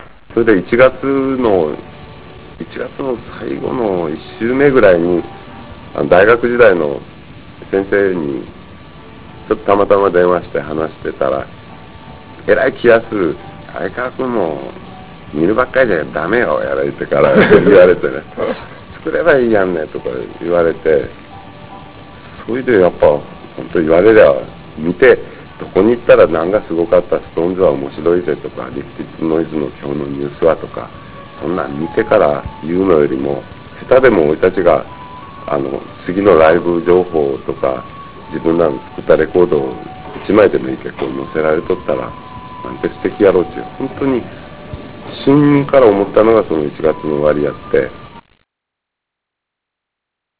鮎川氏のナマの声を聴けるチャンスです。